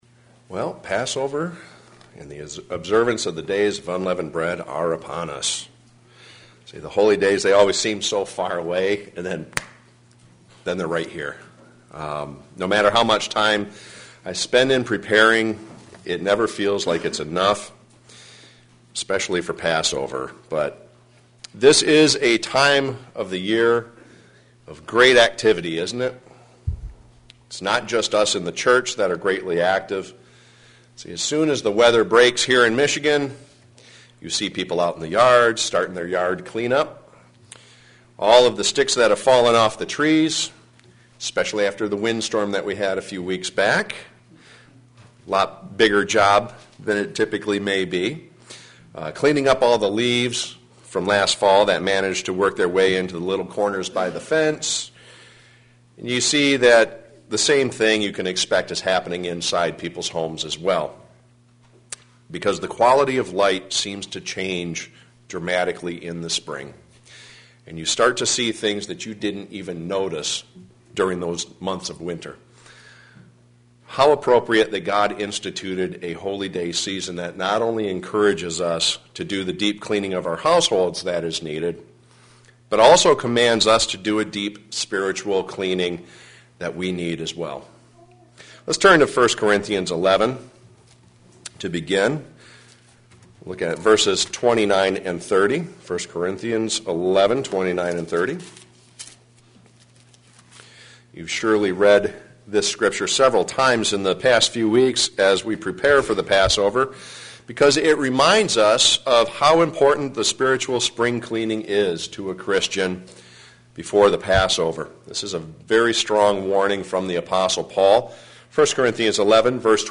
Given in Flint, MI
What difference does it make if we fall short? sermon Studying the bible?